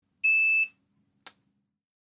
Звуки электросамоката
Звук Выключили электросамокат (бип) (00:02)